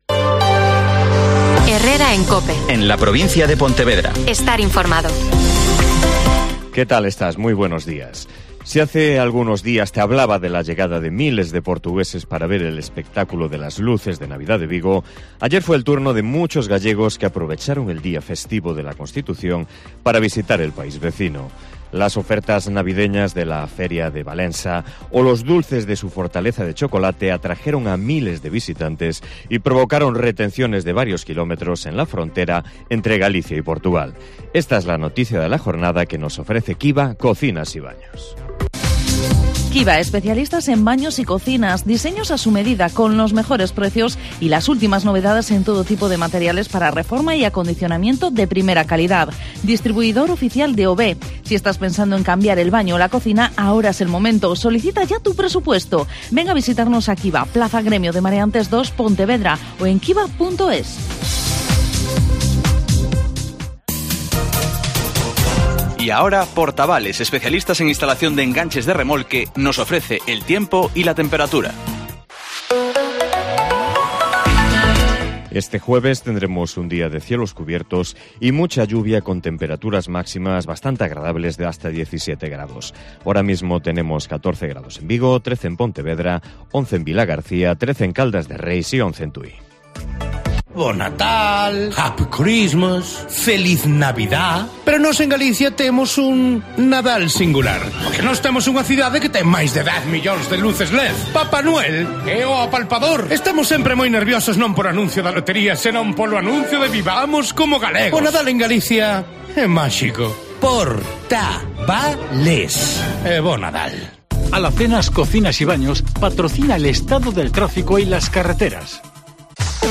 Herrera en COPE Pontevedra y COPE Ría de Arousa (Informativo 08:24h)